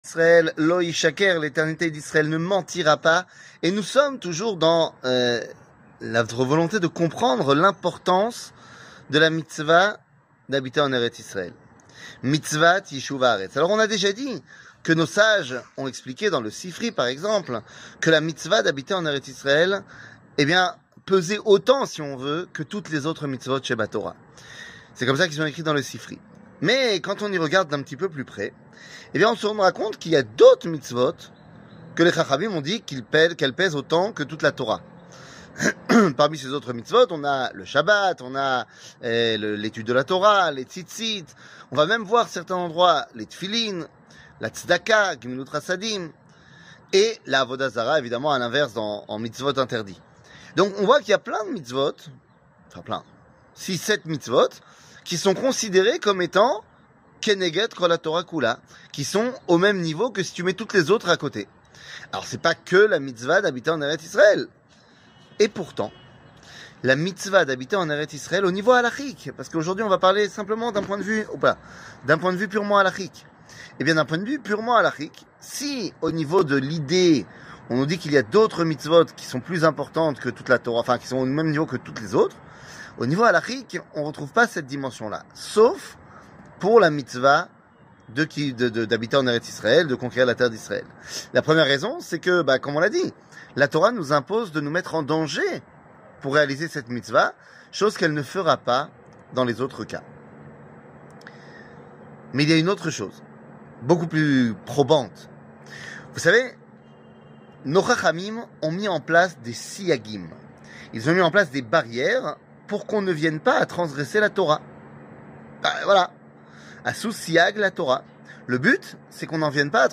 L'éternité d'Israel ne mentira pas ! 13 00:05:06 L'éternité d'Israel ne mentira pas ! 13 שיעור מ 24 אוקטובר 2023 05MIN הורדה בקובץ אודיו MP3 (4.67 Mo) הורדה בקובץ וידאו MP4 (8.42 Mo) TAGS : שיעורים קצרים